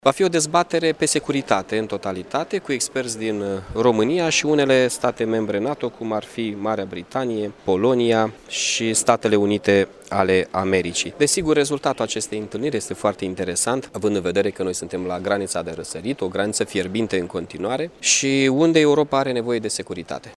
Anunţul a fost făcut, astăzi, la Iaşi, de ambasadorul Ucrainei la Bucureşti, Oleksandr Bankov.